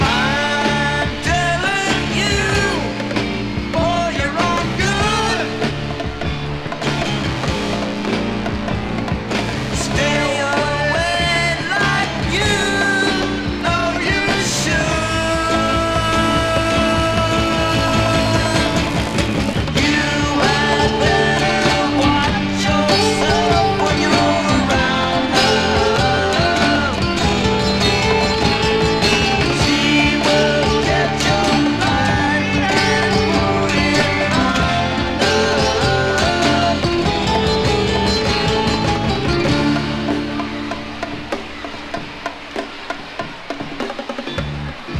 Rock, Psychedelic Rock　USA　12inchレコード　33rpm　Stereo